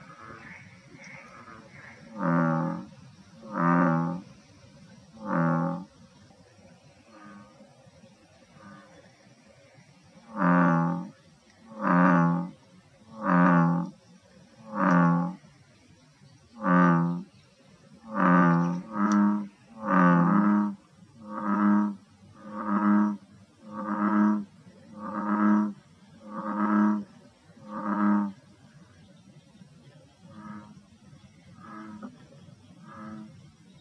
Hier können Sie sich den Ruf des Nordamerikanischen Ochsenfrosch (Lithobates catesbeianus) anhören.
Rufaufnahme